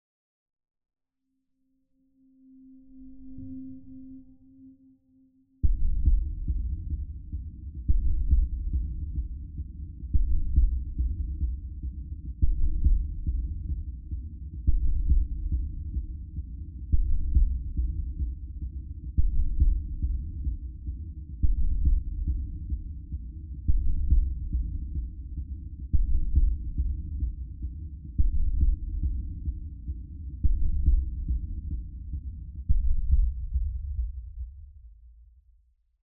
STest1_Left200Hz.flac